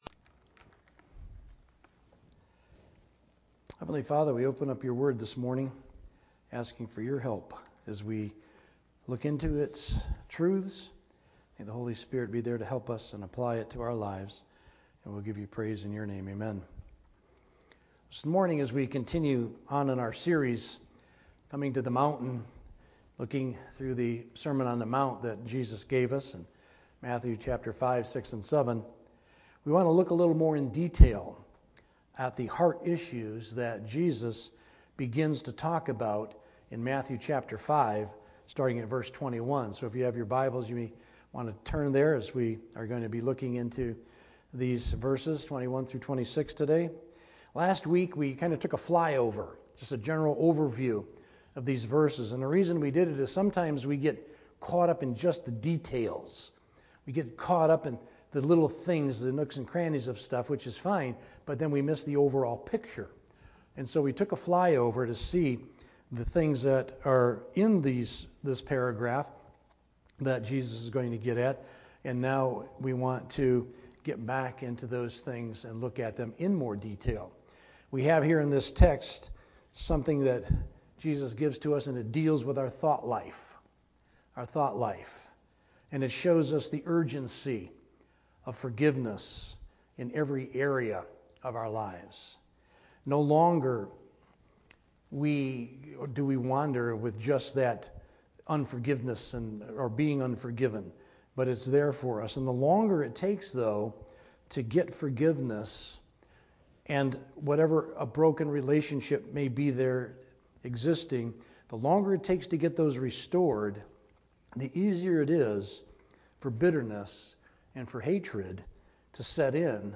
A message from the series "General."
Sermon